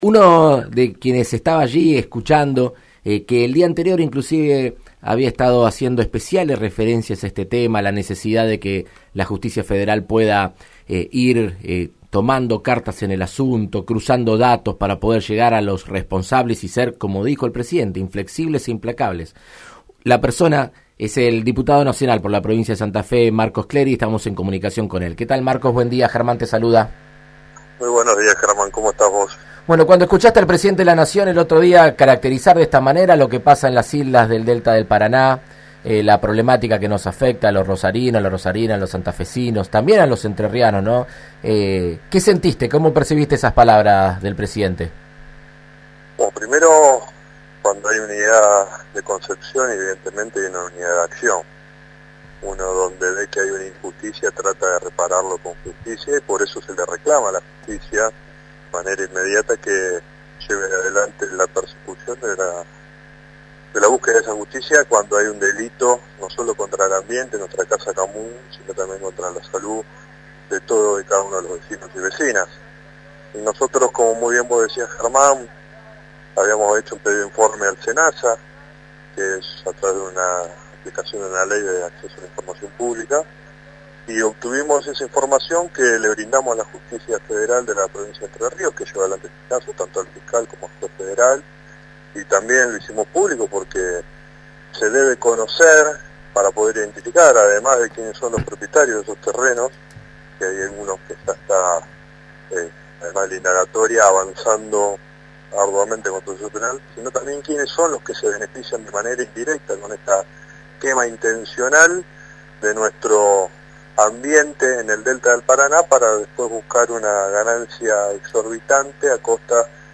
El diputado nacional del Frente de Todos Germán Martínez dialogó acerca del proyecto con su compañero de bancada Marcos Cleri en Argentina Unida contra el Coronavirus.